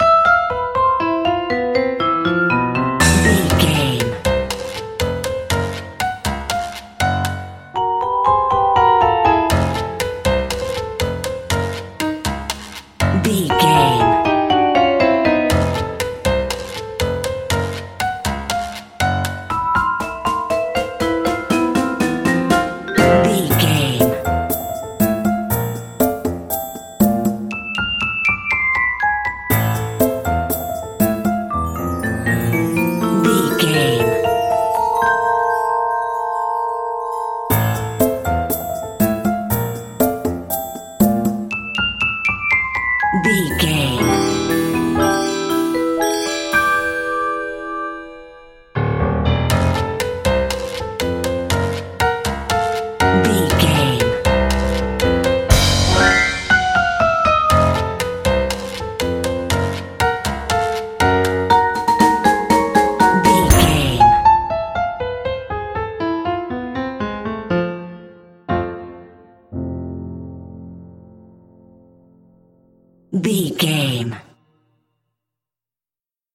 Dorian
percussion
piano
silly
circus
goofy
comical
cheerful
perky
Light hearted
quirky